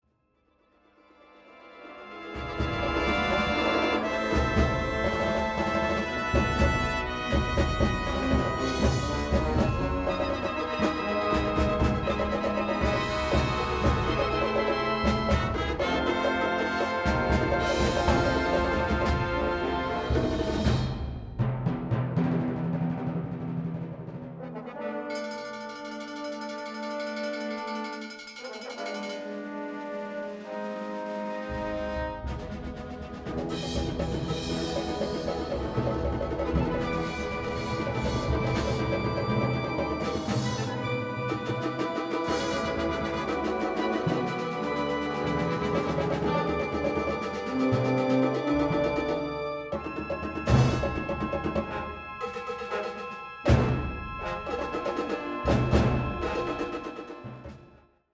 rocking and rolling effusion for concert band